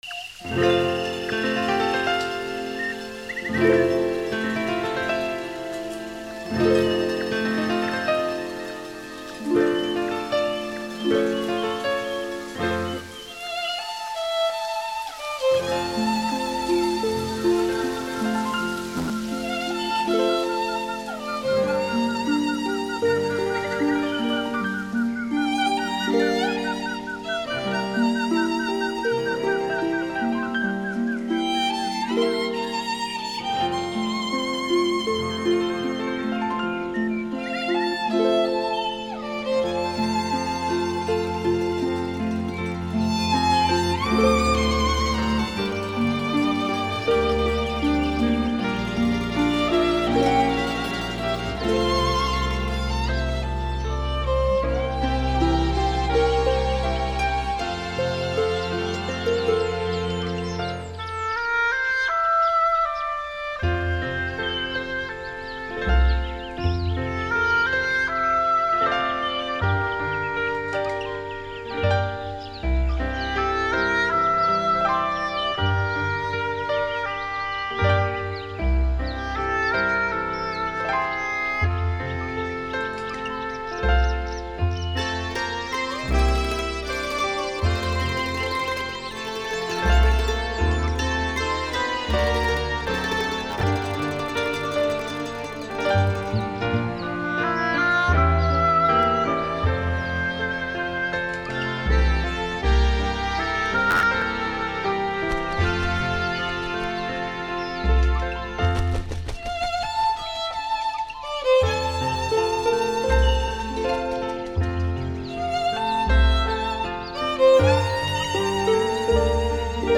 [2005-8-9]小提琴欣赏——My heart is like a violin